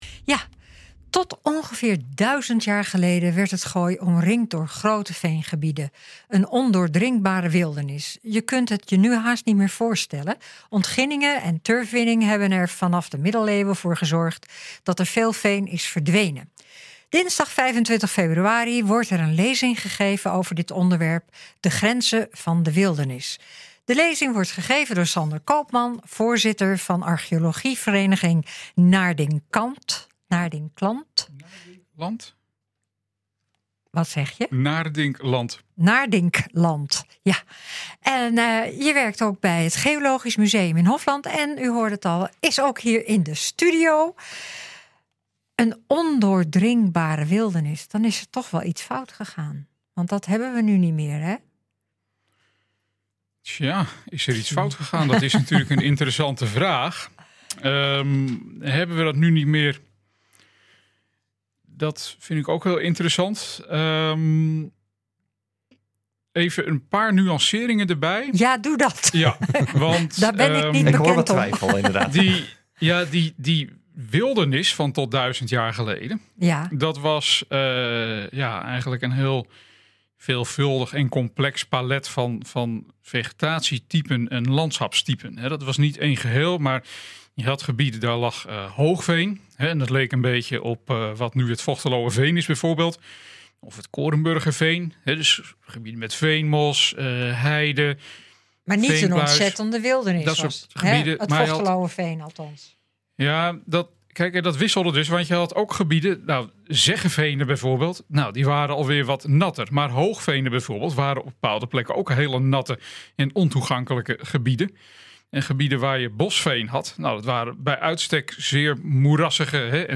NH Gooi Zaterdag - Lezing over verwilderde veengronden
nh-gooi-zaterdag-lezing-over-verwilderde-veengronden.mp3